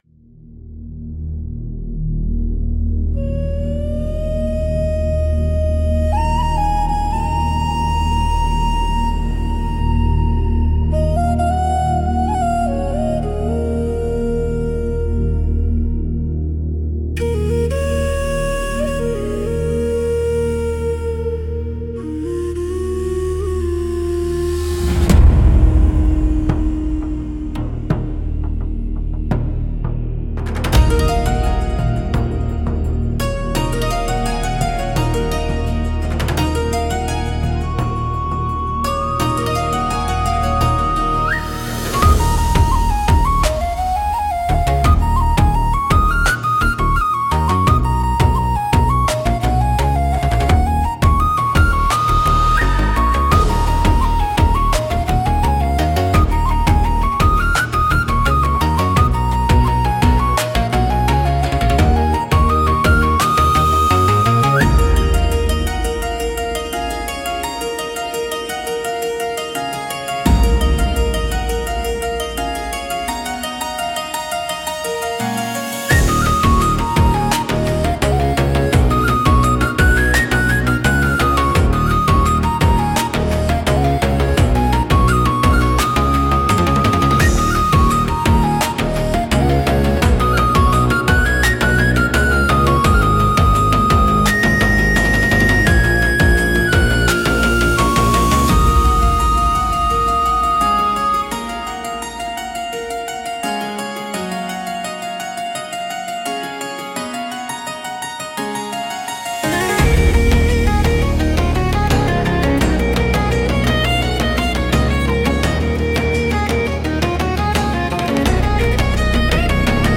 Instrumentals - The Desolate Glen - Grimnir Radio